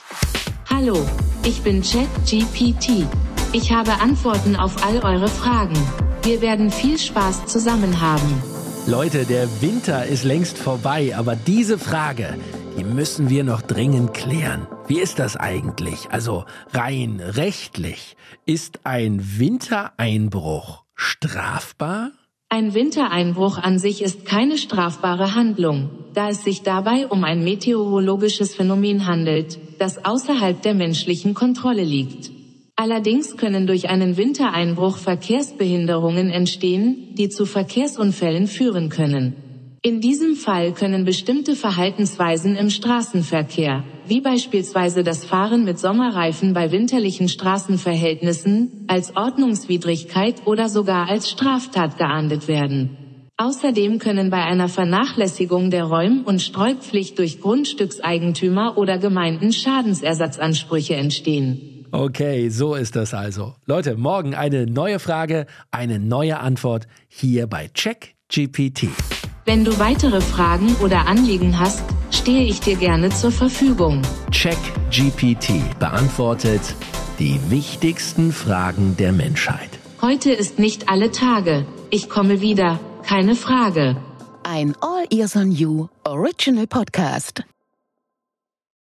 Künstlichen Intelligenz ChatGPT von OpenAI als Co-Host.